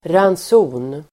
Ladda ner uttalet
Uttal: [rans'o:n]